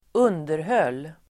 underhöll , maintained Uttal: [²'un:derhöl:]